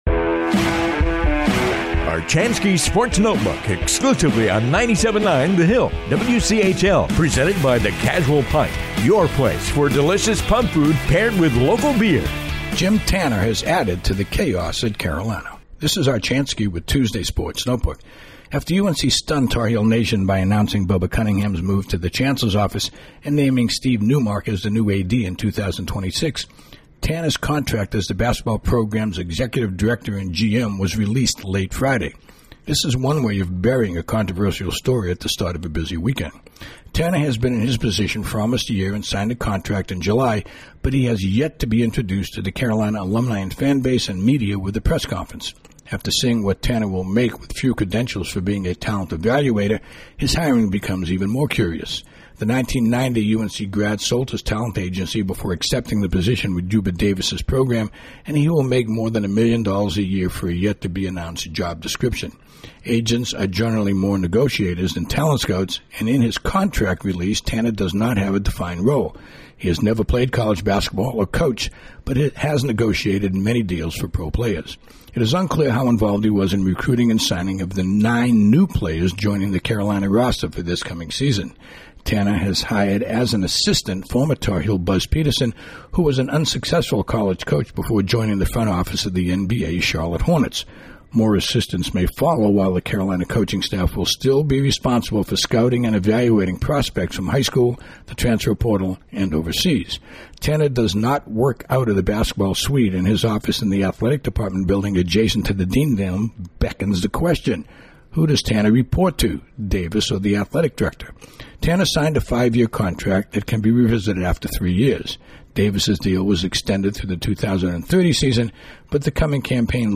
commentary airs daily on the 97.9 The Hill WCHL